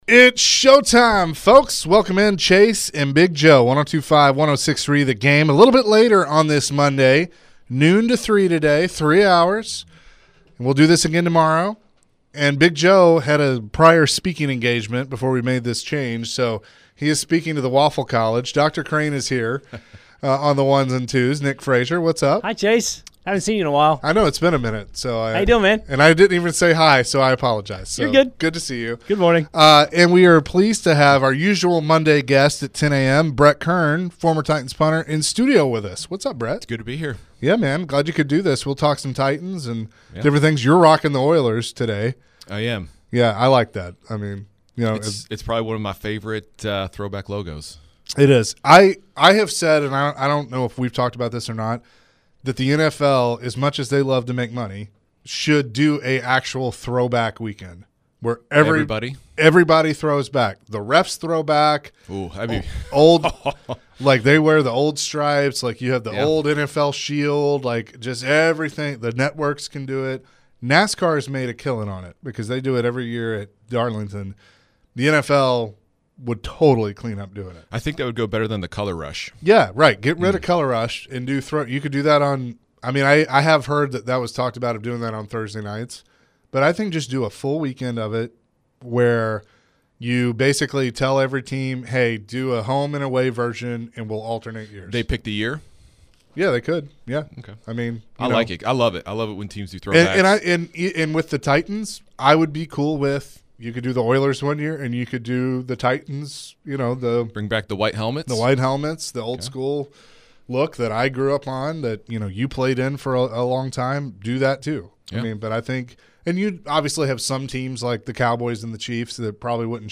Brett analyzed how the Titans will approach the special teams. The guys answered some calls and texts to end the hour.